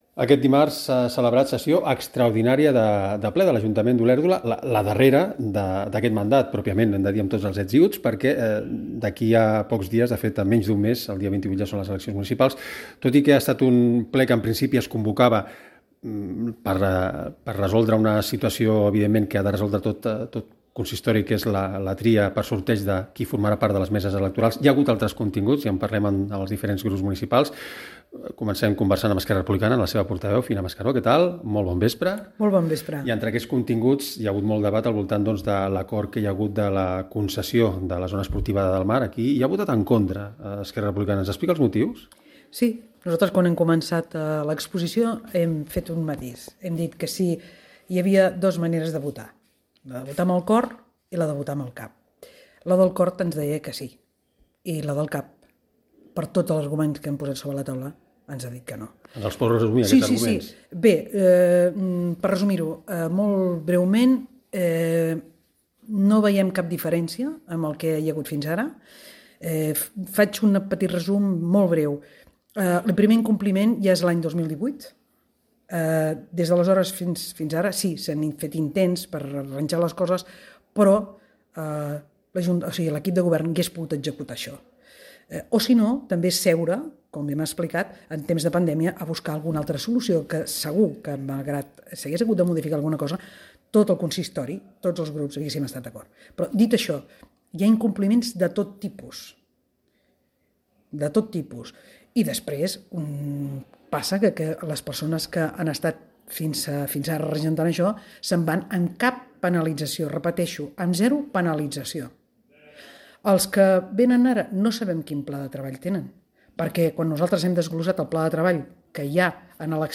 53f3833eb4eb097a873af867b299b6dbee817c48.mp3 Títol Canal 20 Ràdio Olèrdola Emissora Canal 20 Ràdio Olèrdola Titularitat Pública municipal Nom programa Olèrdola actualitat Descripció Informació de la celebració de l'últim ple municipal abans de les eleccions. LEs regidores i regisors dels partits expressen els seus punts de vista sobre els temes debatuts al ple Gènere radiofònic Informatiu